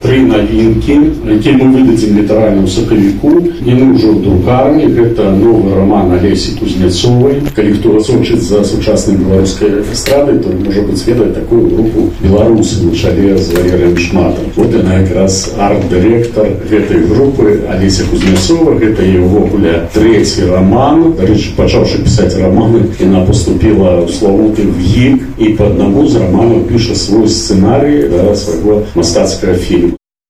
В Барановичах состоялась творческая встреча с известным белорусским писателем и журналистом Александром Карлюкевичем